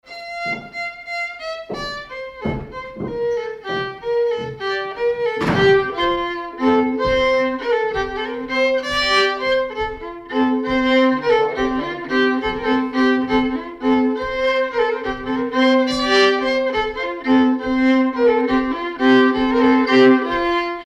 Danse
Villard-sur-Doron
circonstance : bal, dancerie
Pièce musicale inédite